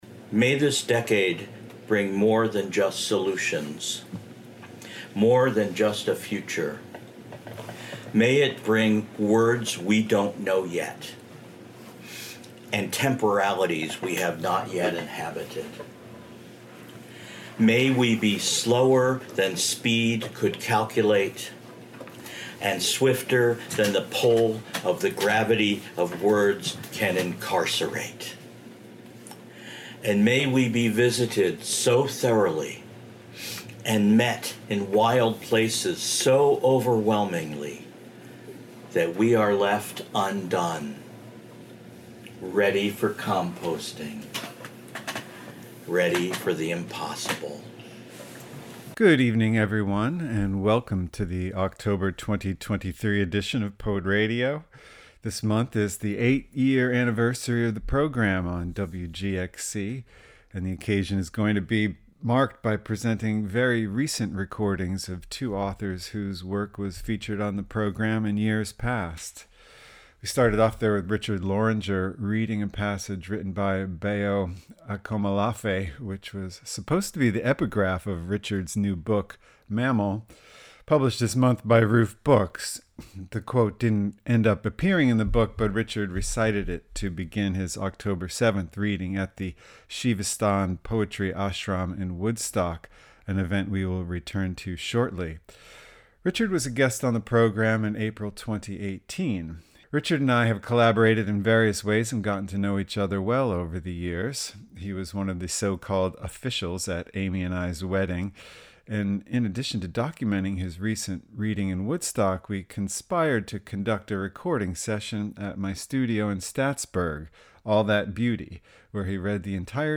This edition of Poet Ray’d Yo features October 2023 recordings of poets previously featured on the program.
Charles Bernstein, winner of the 2019 Bollingen Prize and co-founder of PennSound, reads in Rhinebeck at an event where he received the 11th Annual ‘T’ Space Poetry Award. Blending words and sounds, bringing to the airwaves live performances as well as field and studio recordings by writers the host has crossed paths with over the course of a quarter century.